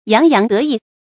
注音：ㄧㄤˊ ㄧㄤˊ ㄉㄜˊ ㄧˋ
讀音讀法：